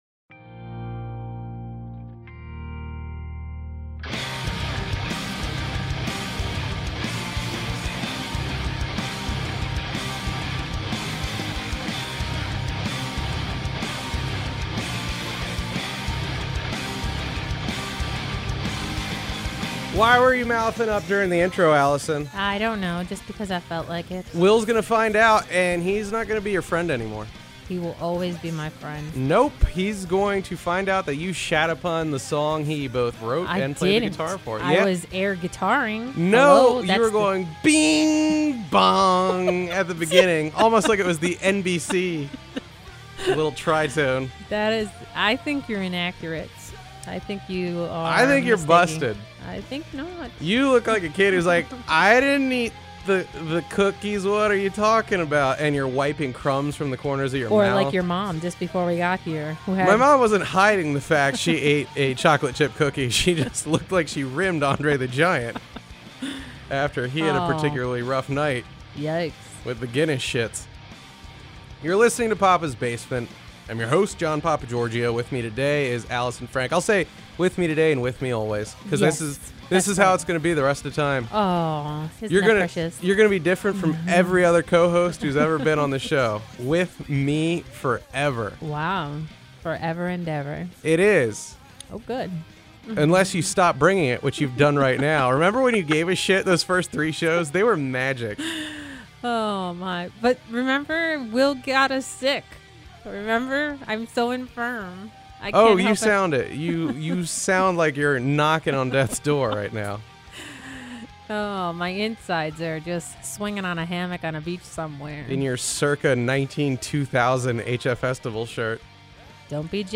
Hilarious, but sad.
Possibly from laughter.